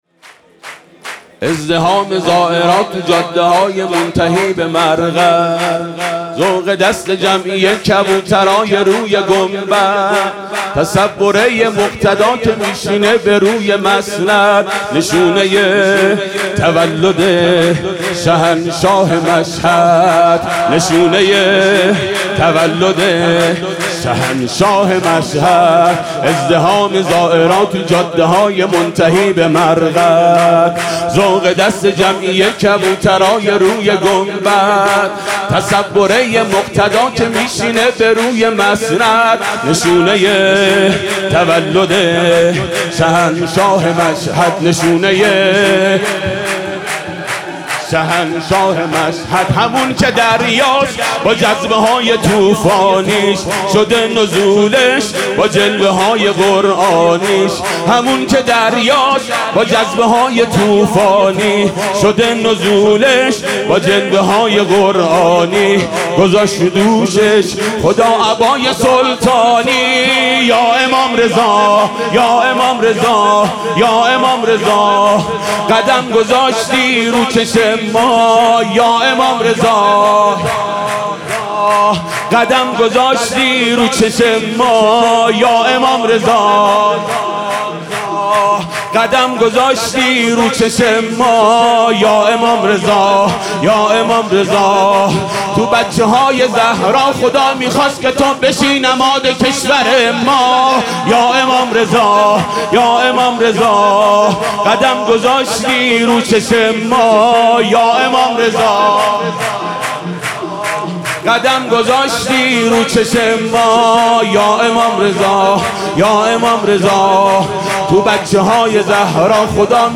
ولادت امام رضا علیه السلام سال ۹۶
سرود